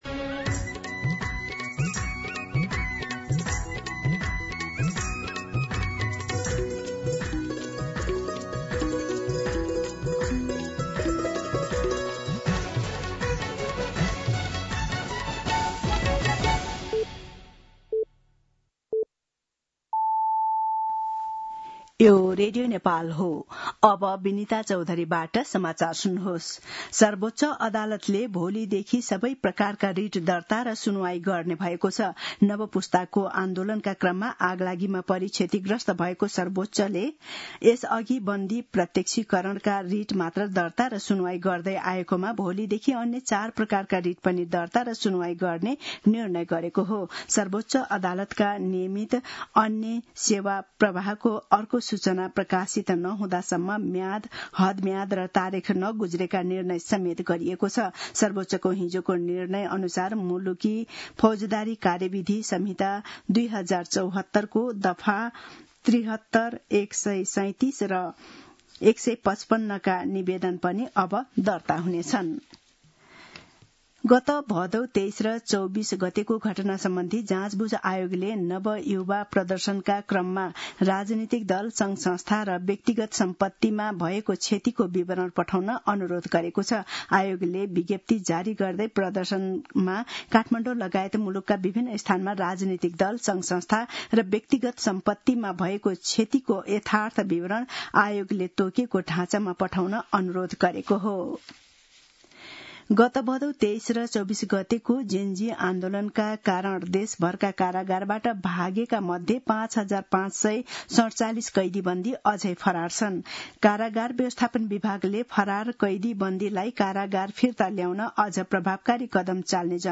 An online outlet of Nepal's national radio broadcaster
दिउँसो १ बजेको नेपाली समाचार : २७ असोज , २०८२
1-pm-Nepali-News-6.mp3